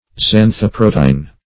Search Result for " xanthoprotein" : The Collaborative International Dictionary of English v.0.48: Xanthoprotein \Xan`tho*pro"te*in\, n. [Xantho- + protein.]